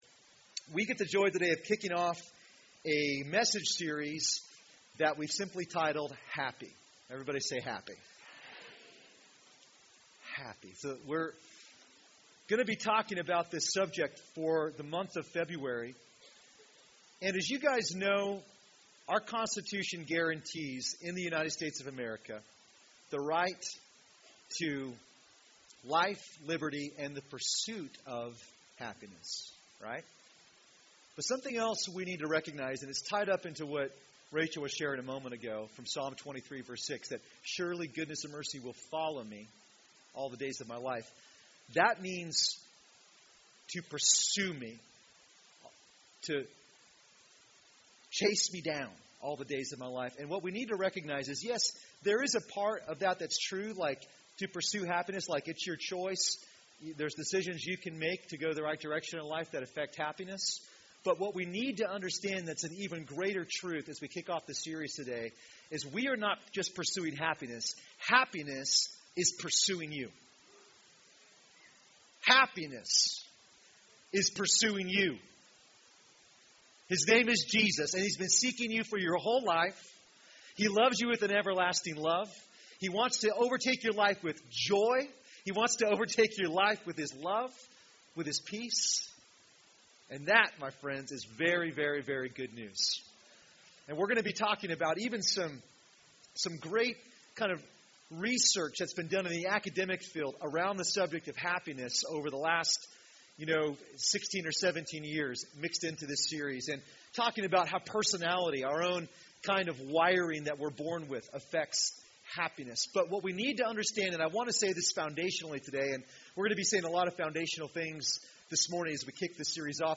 Recorded at New Life Christian Center, Sunday, February 1, 2015 at 11AM.